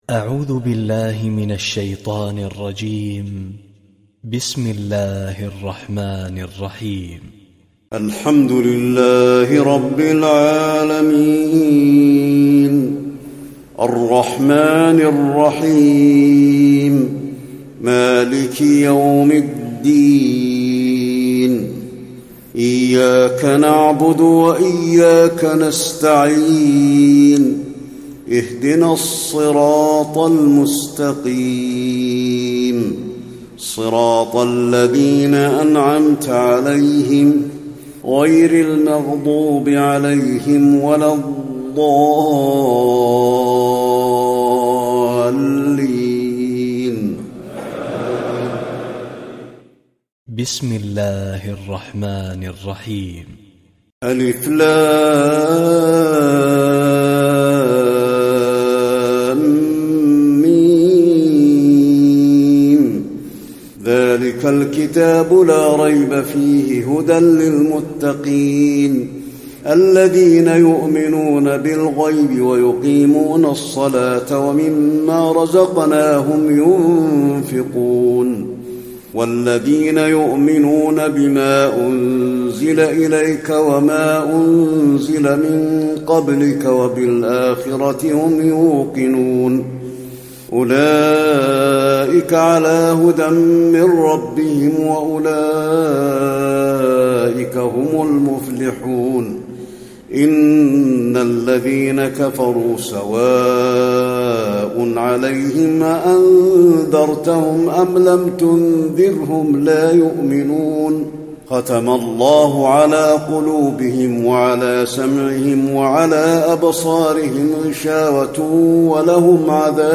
تراويح الليلة الأولى رمضان 1435هـ من سورة البقرة (1-77) Taraweeh 1st night Ramadan 1435H from Surah Al-Baqara > تراويح الحرم النبوي عام 1435 🕌 > التراويح - تلاوات الحرمين